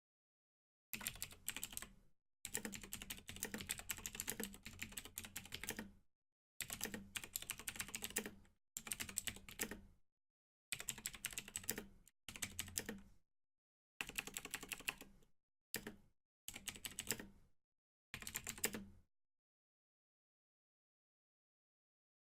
دانلود صدای کیبورد 1 از ساعد نیوز با لینک مستقیم و کیفیت بالا
جلوه های صوتی
برچسب: دانلود آهنگ های افکت صوتی اشیاء دانلود آلبوم صدای تایپ گوشی، کیبورد و ماشین تحریر از افکت صوتی اشیاء